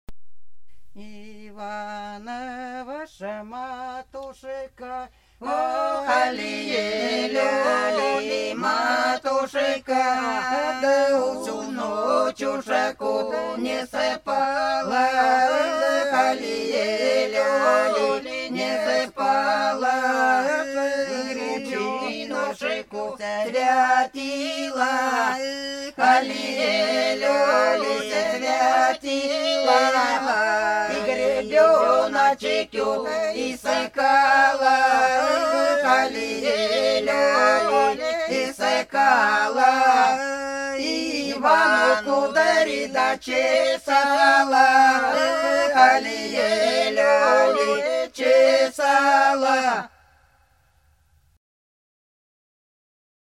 Хороша наша деревня Иванова матушка - свадебная (с. Подсереднее)
22_Иванова_матушка_-_свадебная.mp3